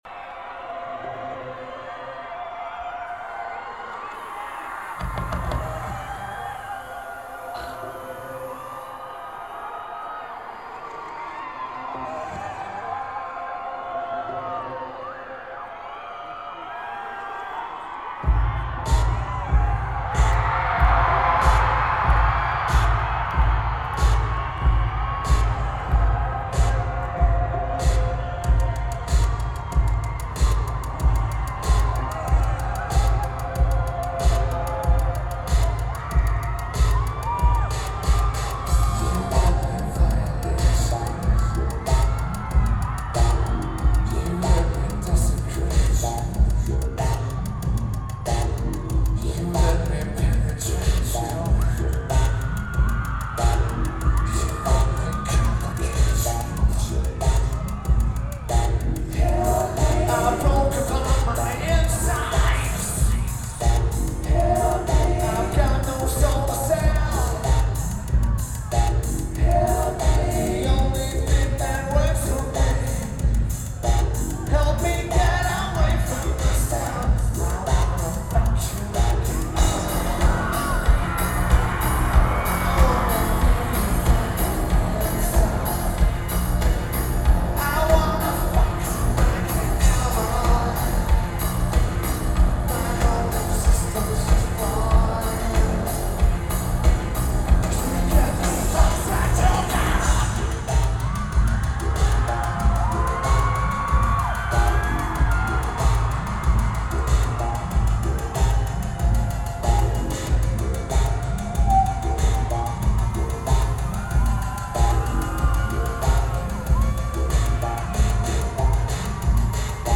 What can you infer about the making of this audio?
McNichols Arena Lineage: Audio - AUD (Sonic Studio DSM-6 + Sony TCD-D7)